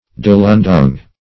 Search Result for " delundung" : The Collaborative International Dictionary of English v.0.48: Delundung \De*lun"dung\, n. [Native name.]